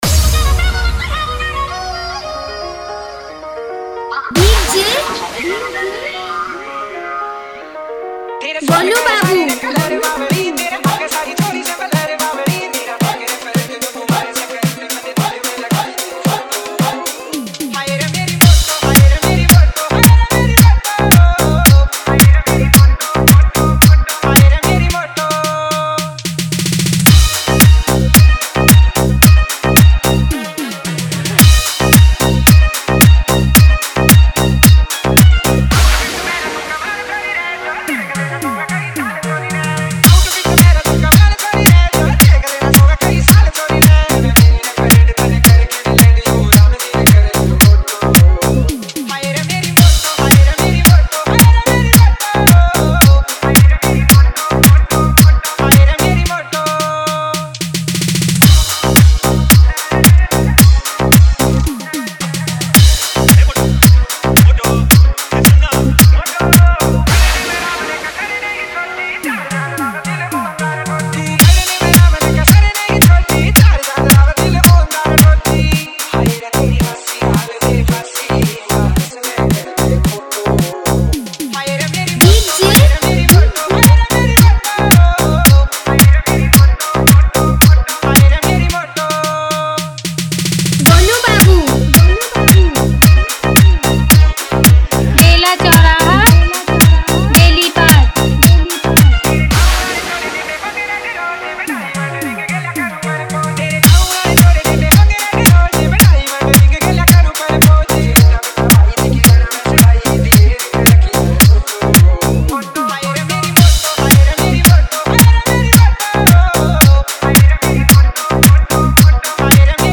Category:  New Hindi Dj Song 2020